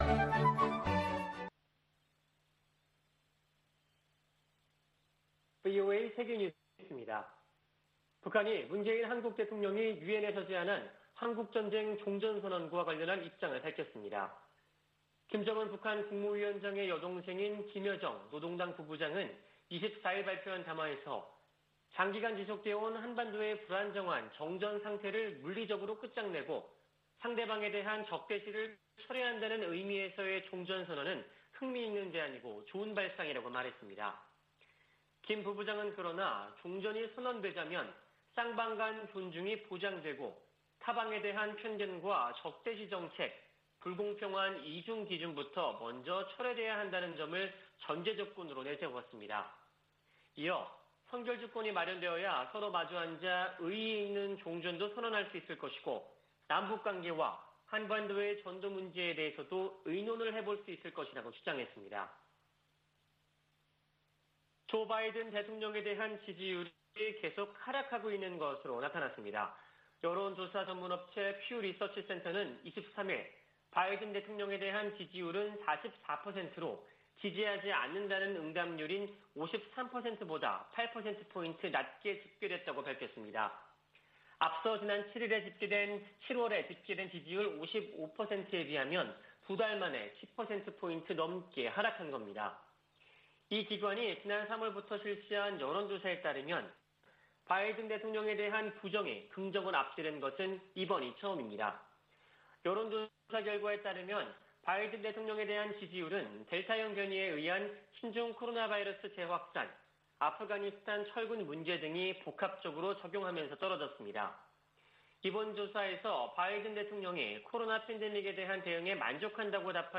VOA 한국어 아침 뉴스 프로그램 '워싱턴 뉴스 광장' 2021년 9월 25일 방송입니다. 미국은 북한 식량난과 관련해 주민들의 안위를 우려하고 있다고 미 고위 당국자가 밝혔습니다. 유엔이 북한을 또다시 식량부족국으로 지정하며 코로나 여파 등으로 식량안보가 더 악화했다고 설명했습니다.